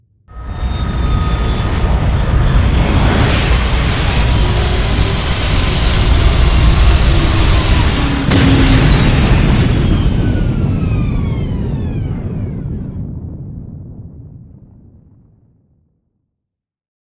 ships / movement / landing2.ogg
landing2.ogg